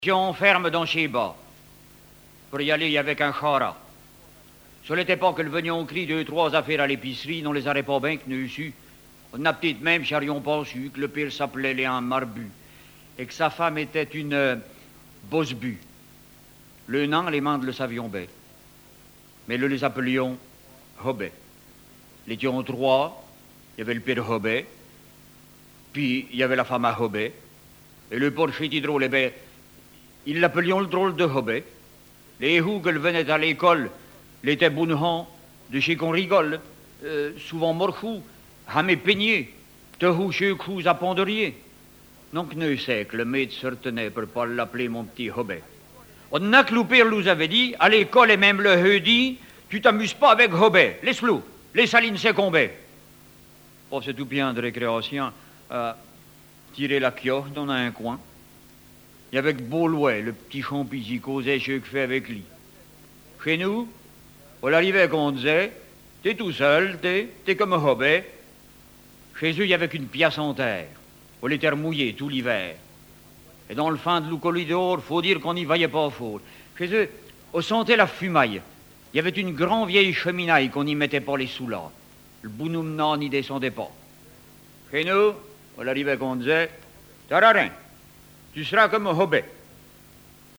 Genre sketch
histoires en patois poitevin
Catégorie Récit